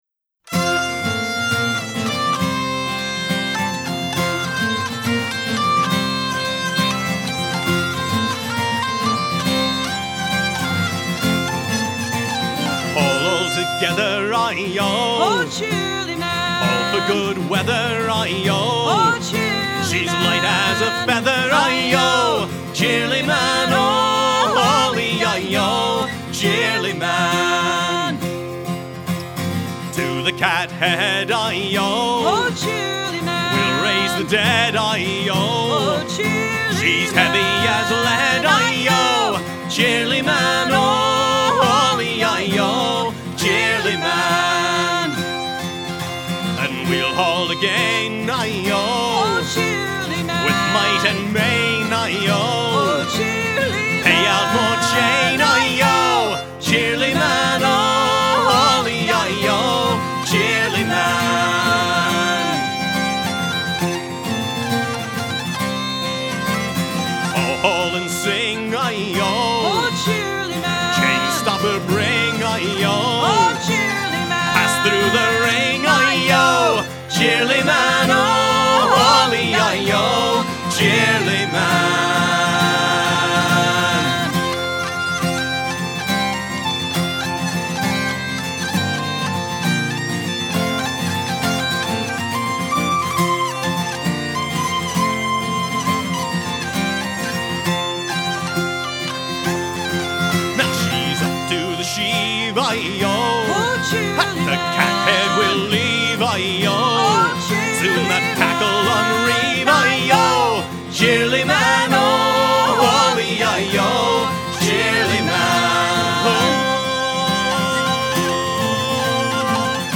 Stan Hugill, in Shanties From the Seven Seas, refers to Cheerily Man as one of the oldest of all known sea shanties.
We follow it with a traditional English dance tune, The Moon and Seven Stars.